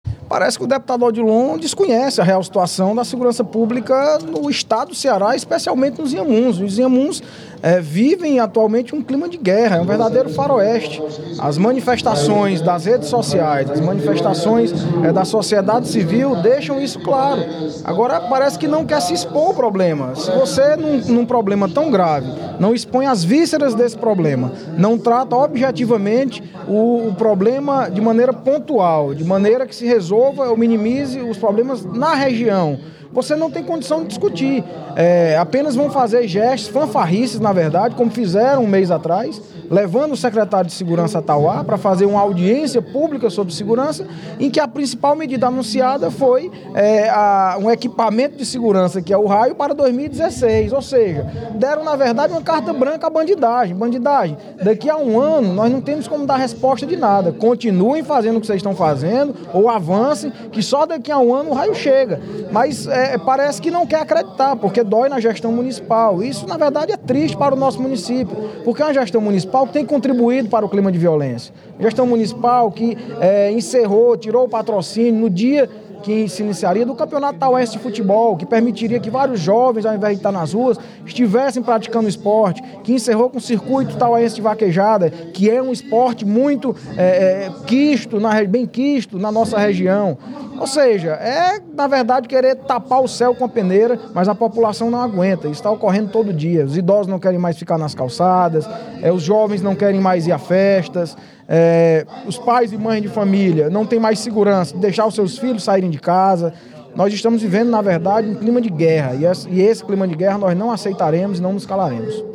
O deputado Audic Mota (PMDB) lamentou, durante o primeiro expediente da sessão plenária desta terça-feira (06/10), a sensação de insegurança vivenciada pelos habitantes do município de Tauá nos últimos anos.
Em aparte, o deputado Odilon Aguiar (Pros) negou que haja inércia e descaso da Prefeitura de Tauá em relação ao problema da segurança pública no município, salientando que a onda de violência não tem atingido apenas a cidade de Tauá, mas toda a região dos Inhamuns.